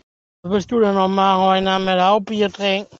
Bier trinken.wav